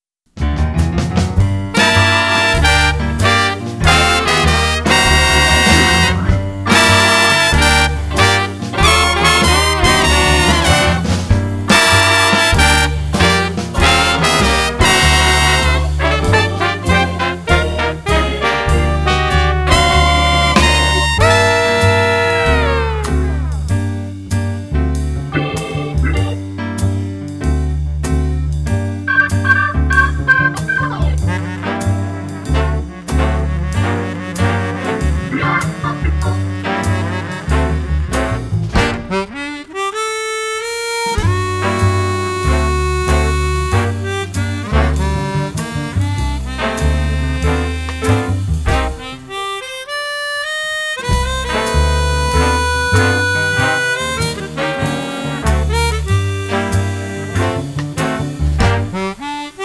"big band" version
Traditional Jazz Combo, 11 songs